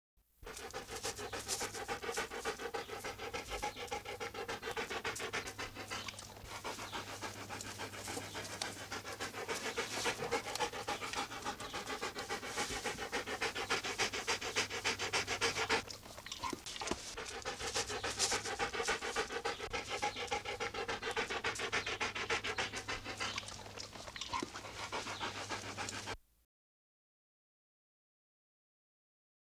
animal
Jackal Panting with Occasional Licks